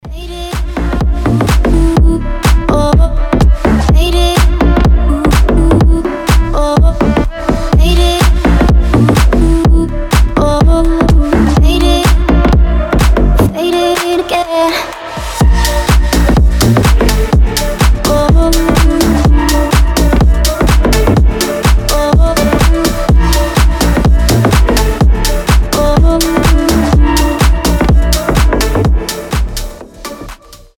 • Качество: 320, Stereo
deep house
EDM
басы
slap house